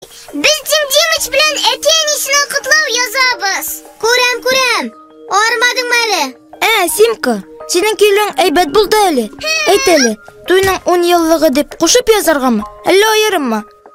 Звуки общения Фиксиков на татарском языке